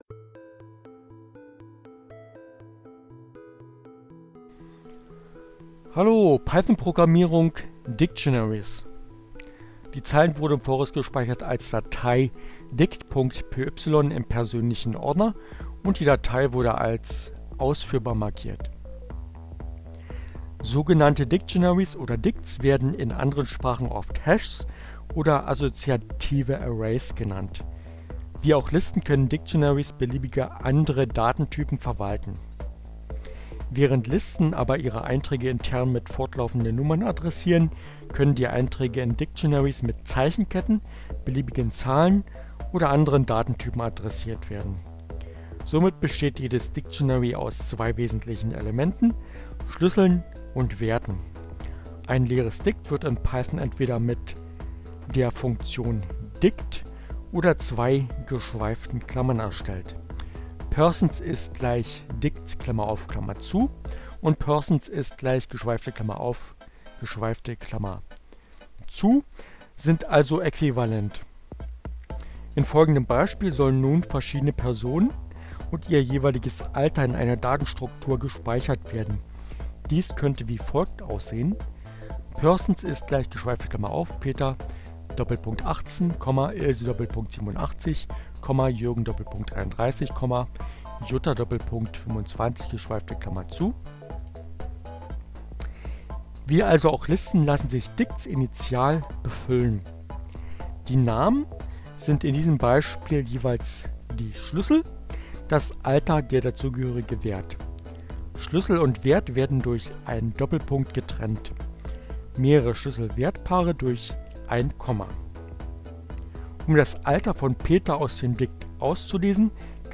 Tags: CC by-sa, Linux, Neueinsteiger, Ogg Theora, ohne Musik, screencast, ubuntu, Python, Programmierung, Unity, Dict, Dictionary